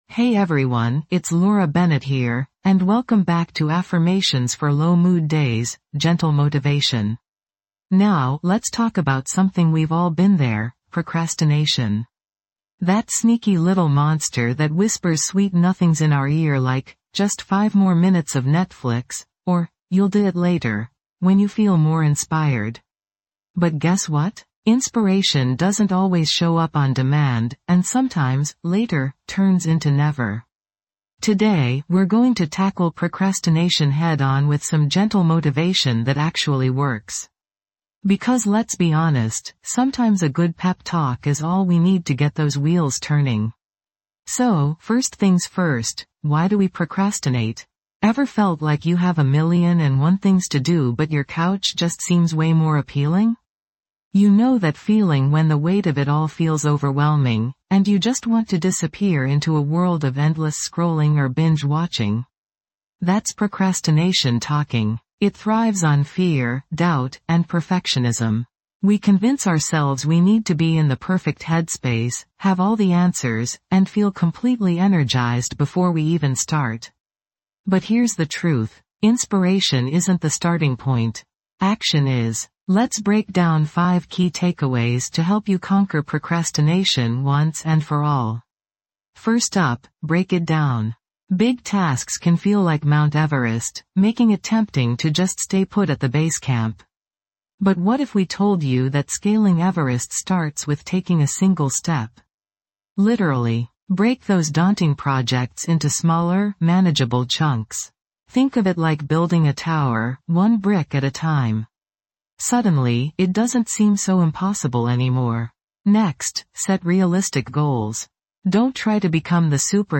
This podcast delivers daily doses of gentle affirmations designed specifically to combat feelings of sadness, negativity, and self-doubt. Through soothing voices and carefully crafted phrases, we'll guide you towards self-compassion, resilience, and hope.